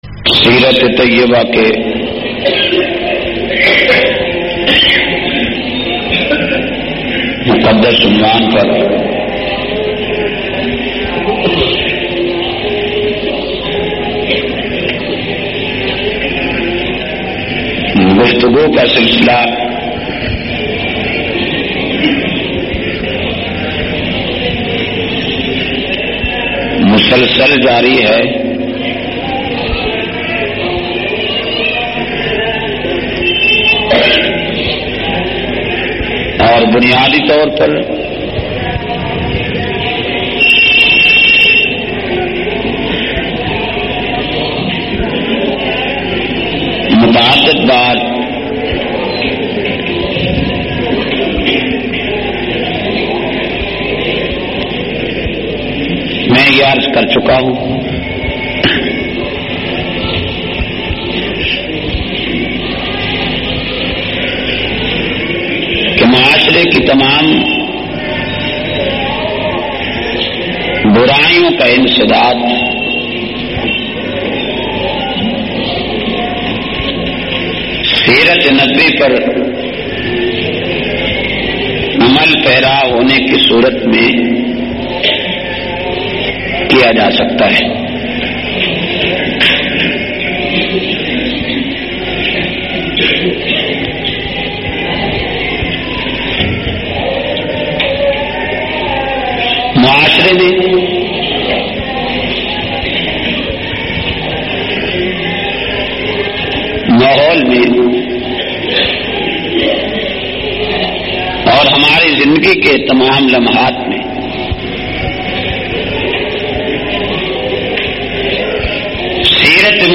284- Nizam e Shariat-Jumma,Jhang.mp3